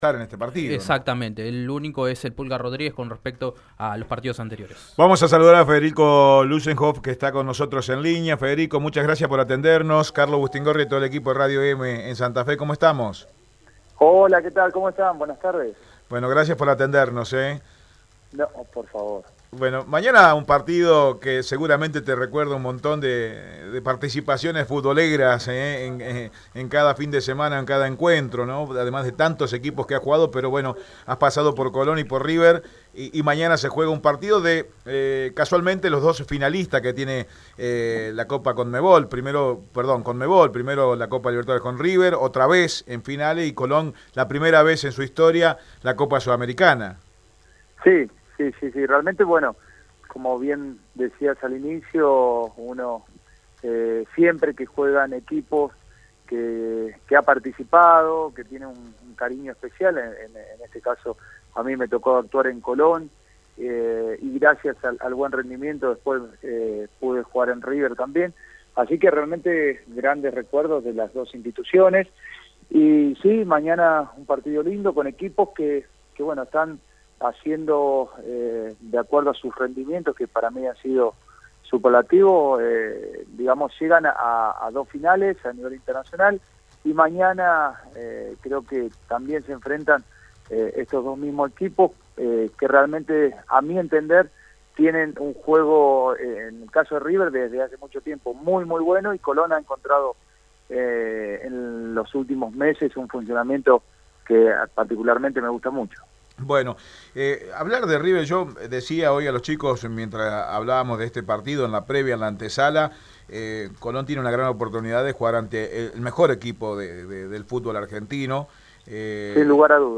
En Radio Eme Deportivo, dialogamos con un ex jugador del sabalero que tuvo un paso en River, Federico Lussenhoff que habló sobre el encuentro de mañana entre Colón y el millonario. Además, se refirió a la final de la Sudamericana.